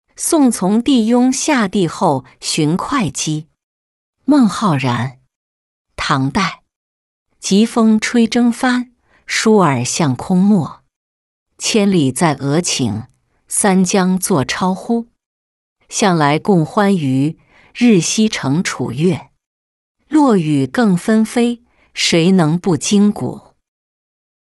送从弟邕下第后寻会稽-音频朗读